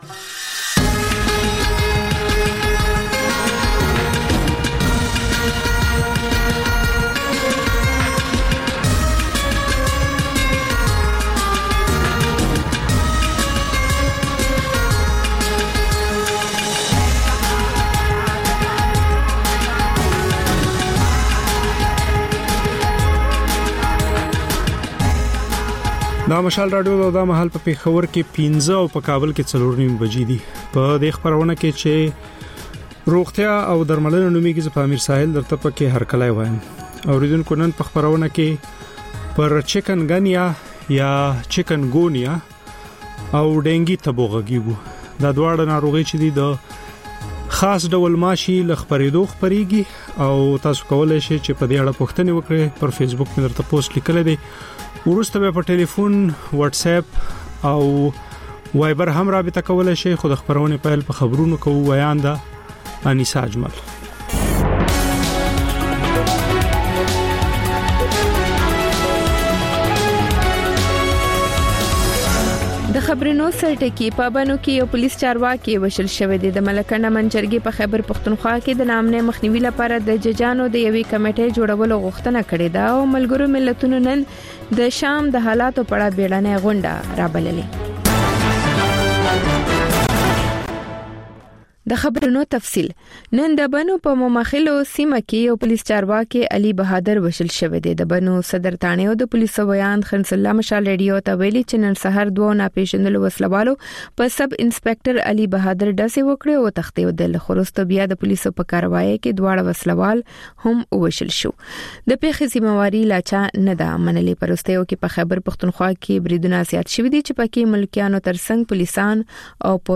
د مشال راډیو ماښامنۍ خپرونه. د خپرونې پیل له خبرونو کېږي، بیا ورپسې رپورټونه خپرېږي. ورسره یوه اوونیزه خپرونه درخپروو.